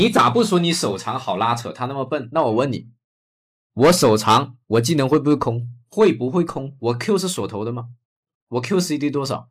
高影响力的广告旁白配音
文本转语音
说服力音调
我们的 AI 提供一种权威、专业的音调，能立即与您的受众建立信任。